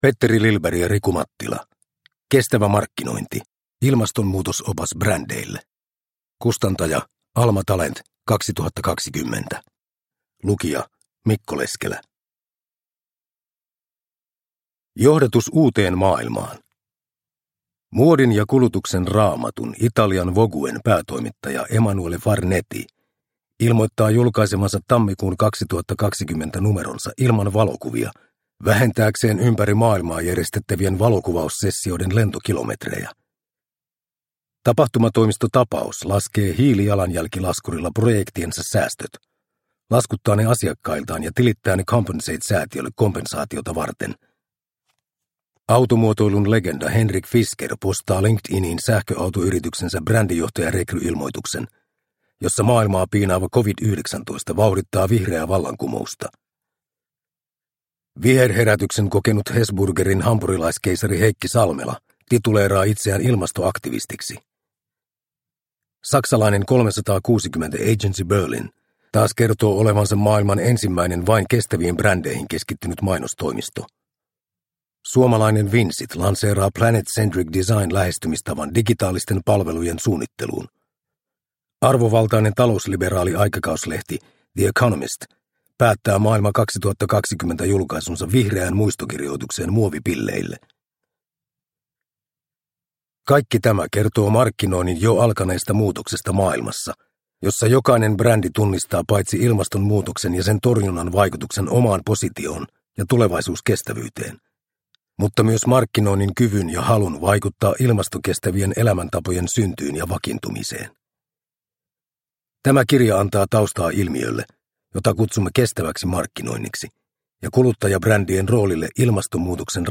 Kestävä markkinointi – Ljudbok – Laddas ner